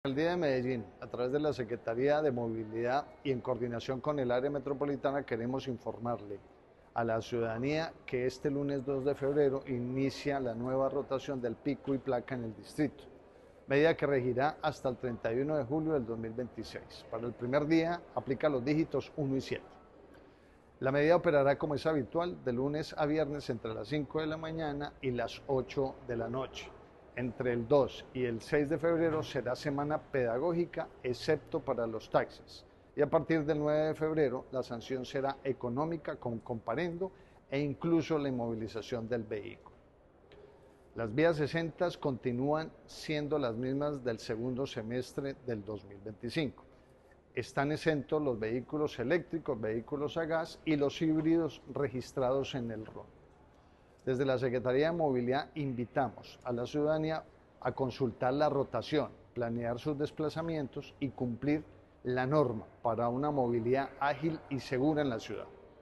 Declaraciones-del-secretario-de-Movilidad-Pablo-Ruiz-2-1.mp3